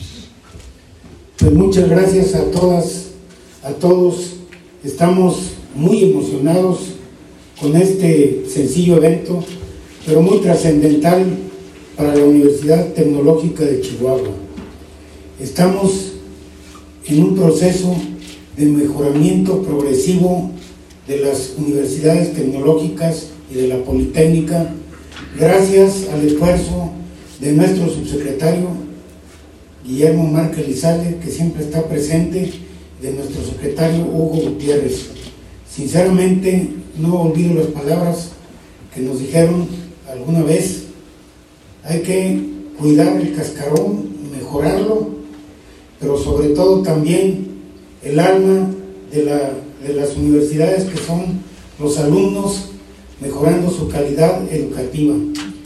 audio-kamel_athie_flores_rector_de_la_utch.mp3